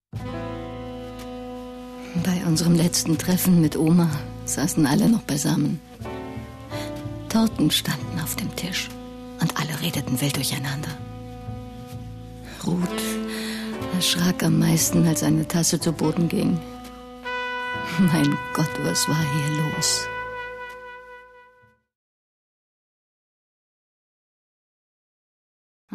ausdrucksstark,charaktervoll, auch dunkles Timbre, sprachrhythmisch gewandt, warmer Erzählton, klarer journalistischer Ton, klangvoll, geschmeidig
Sprechprobe: Werbung (Muttersprache):
female german speaker with warm and colourful voice